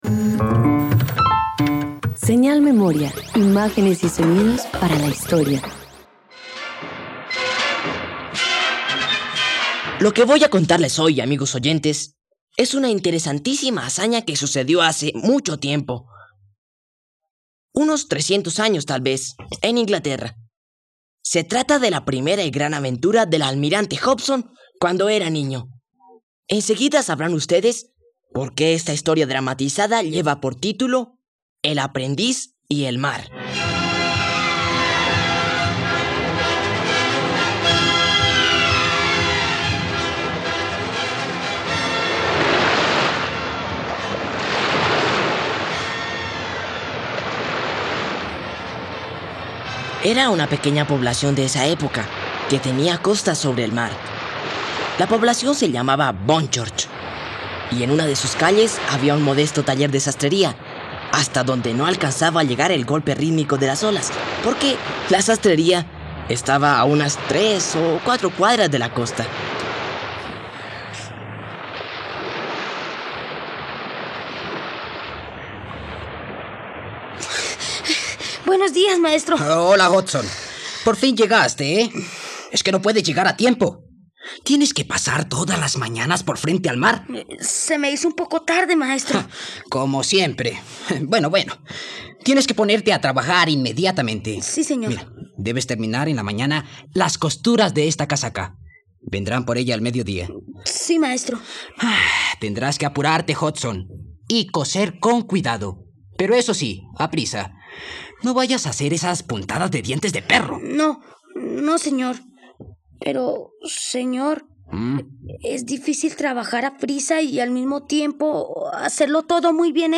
El aprendiz y el mar - Radioteatro dominical | RTVCPlay